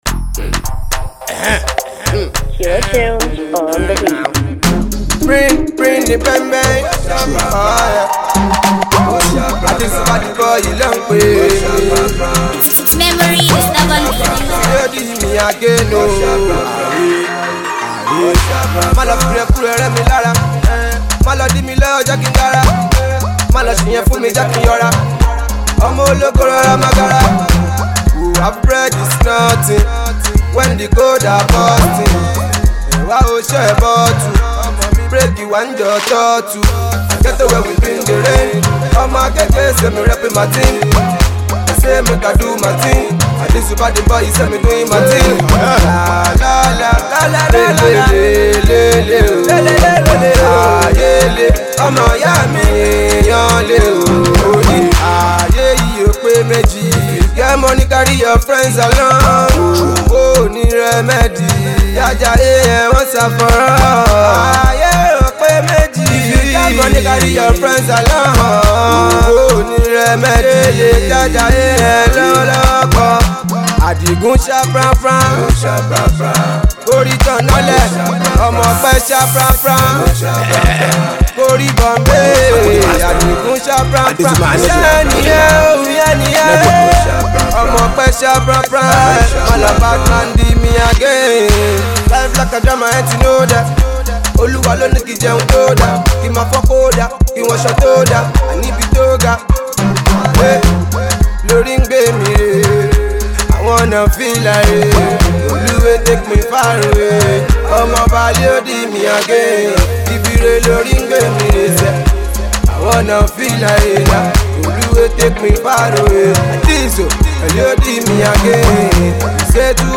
blazing Street hit